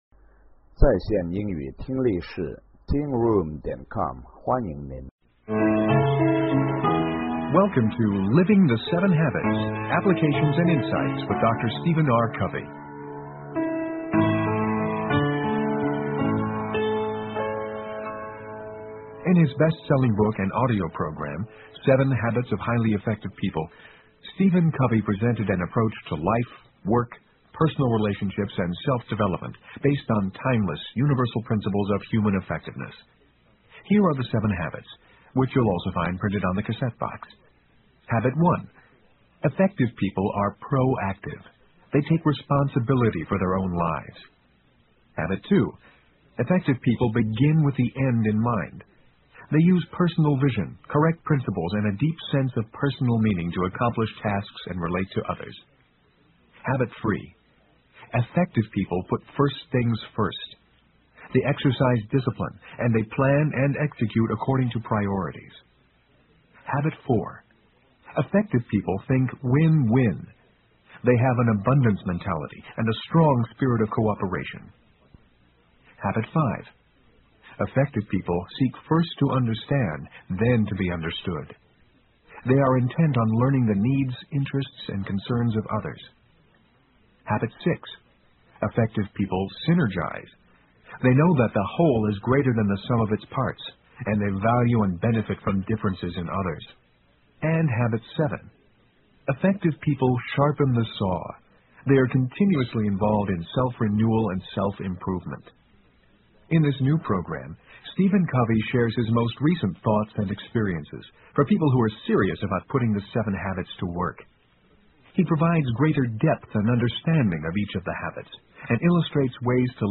有声畅销书：与成功有约01 听力文件下载—在线英语听力室